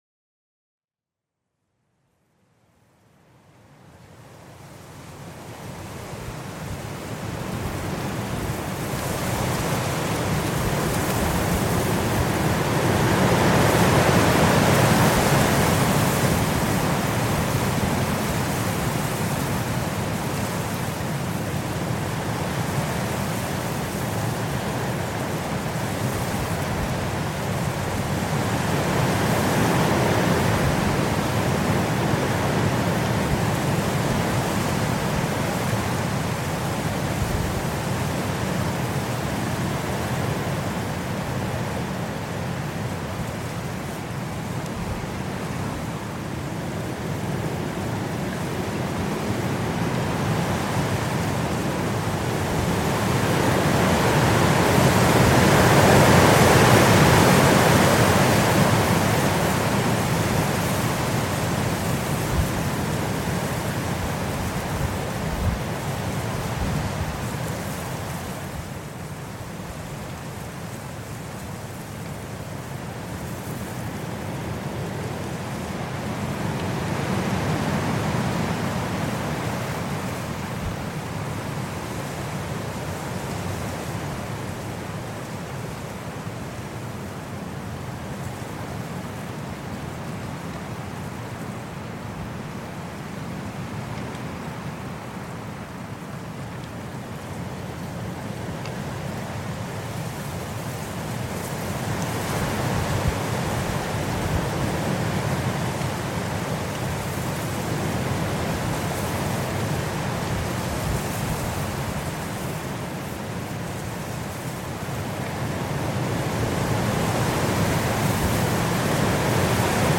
WEISSE STILLE-HYPNOSE: Schneesturm für tiefste Entspannung